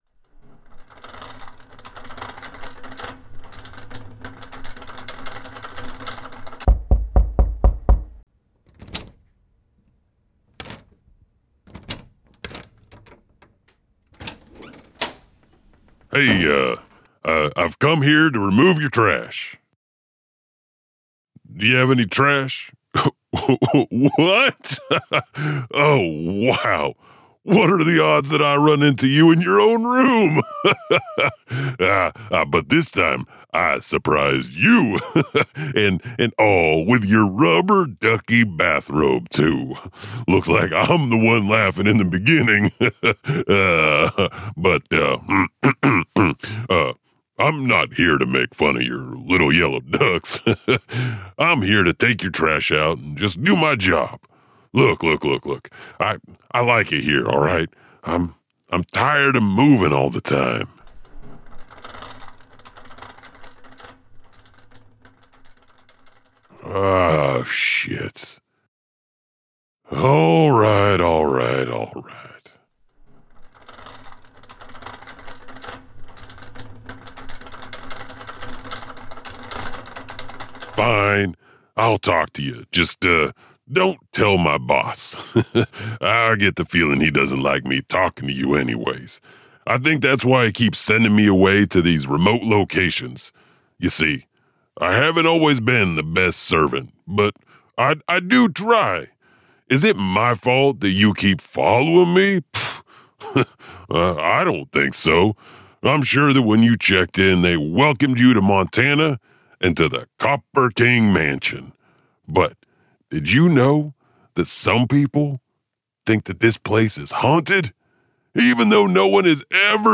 Tales from the Janitor - Join a mysterious custodial host for a telling of tales strange and dark on a journey through the haunted nooks and crannies of these great American states.